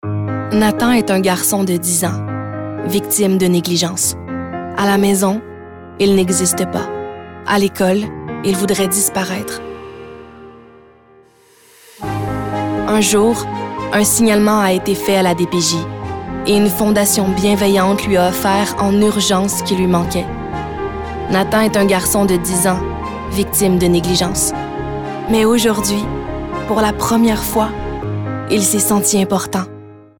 Démos voix